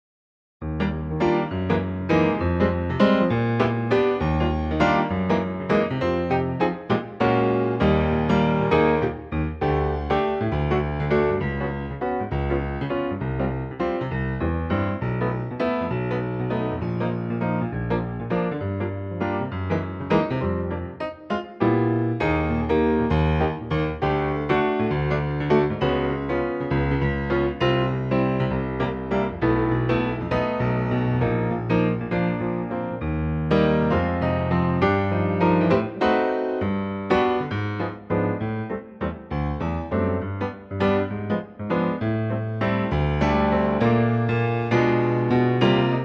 key Eb
key - Eb - vocal range - Bb to G (optional Bb top note)
gem in waltz time, arranged for piano only.